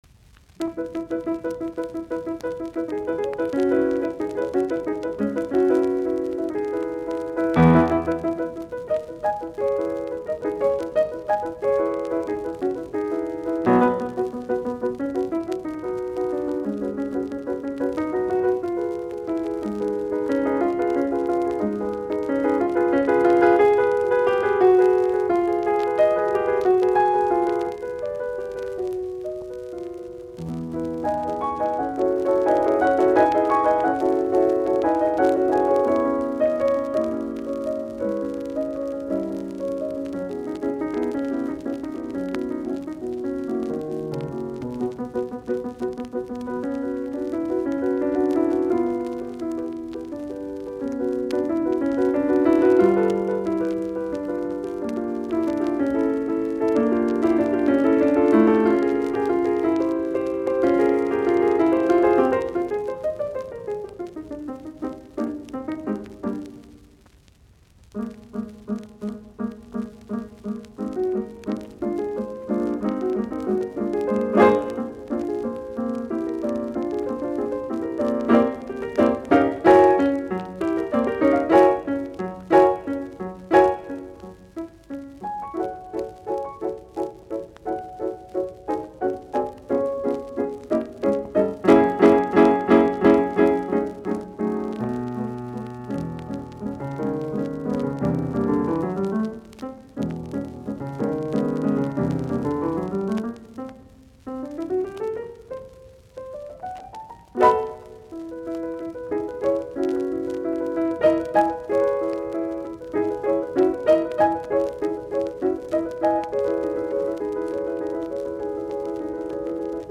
Soitinnus : Piano